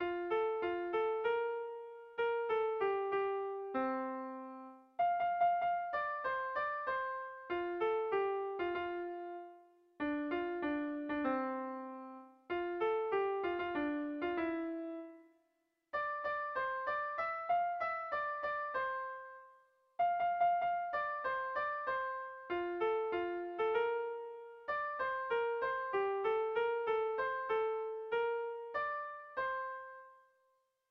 ABDE